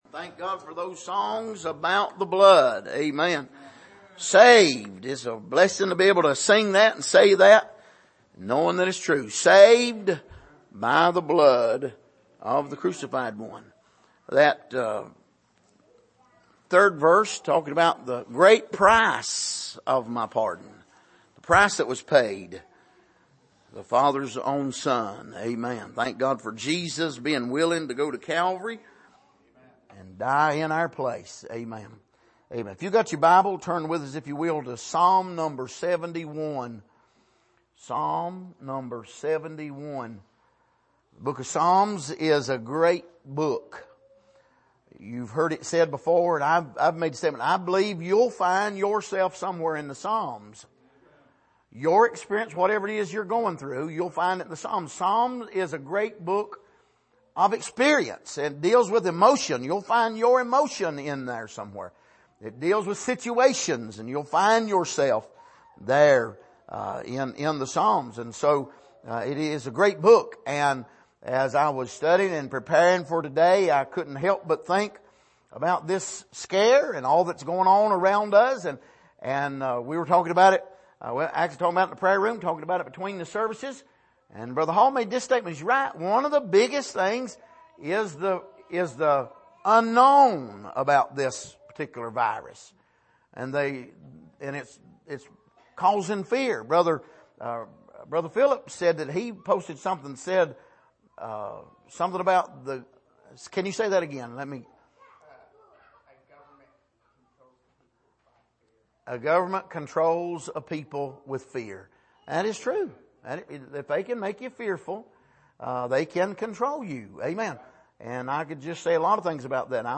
Passage: Psalm 71:1-9 Service: Sunday Morning